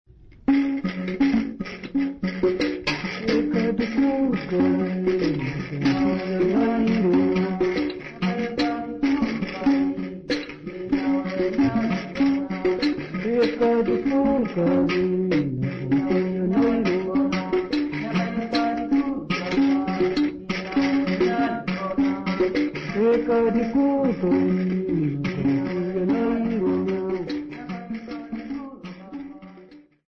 Sambiu church music workshop participants
Sacred music Namibia
Mbira music Namibia
Africa Namibia Sambiu mission, Okavango sx
field recordings
Church song accompanied by the mbira type instrument sisanti and indingo played at both lower and upper key.